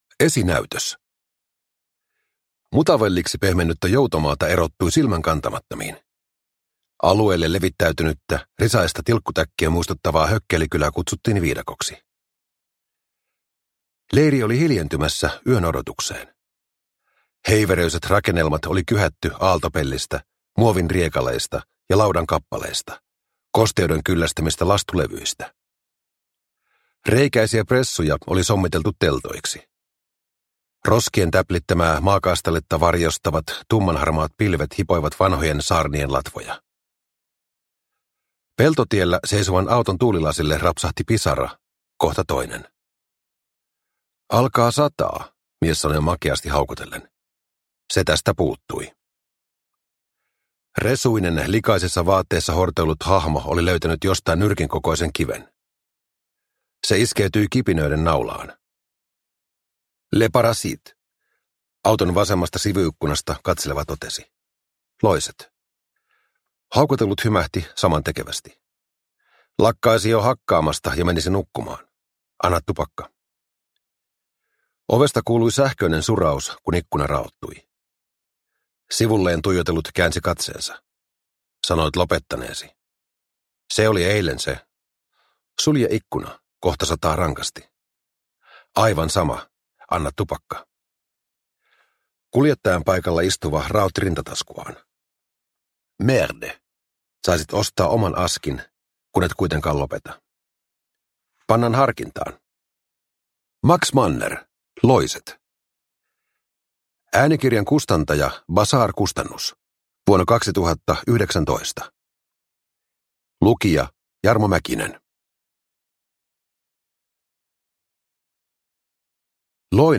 Loiset – Ljudbok – Laddas ner